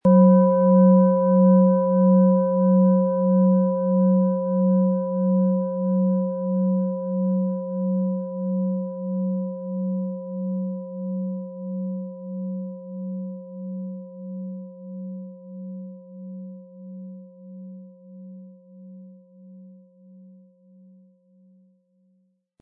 Planetenton 1
Planetenschale® Sensibel und Einfühlend sein & Sich selbst helfen können mit Mond, Ø 12,5 cm inkl. Klöppel
MaterialBronze